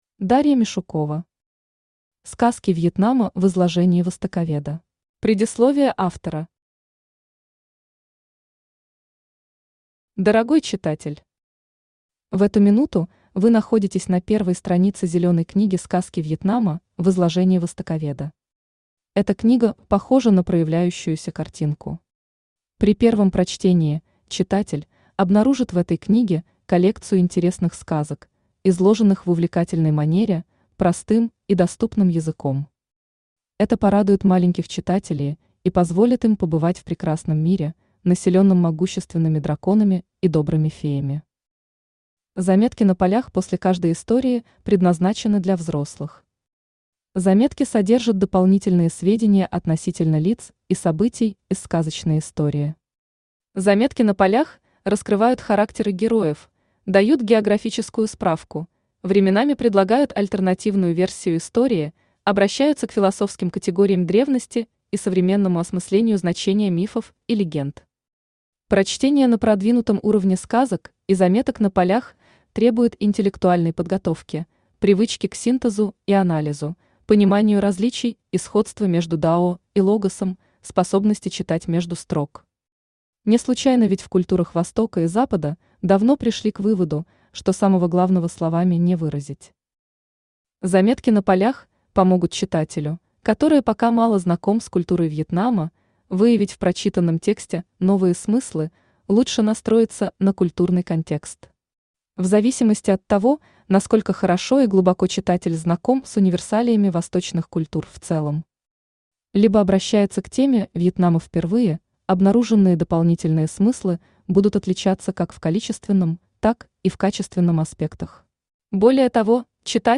Аудиокнига Сказки Вьетнама в изложении востоковеда | Библиотека аудиокниг
Читает аудиокнигу Авточтец ЛитРес.